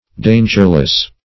Dangerless \Dan"ger*less\, a. Free from danger.